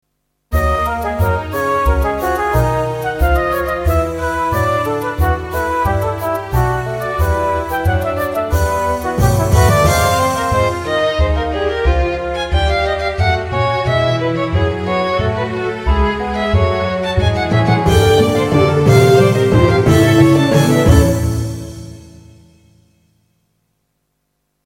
Hetzelfde thema maar nu in een vrolijker jasje. De tovenaar ontvangt de bewoners uit het dorp.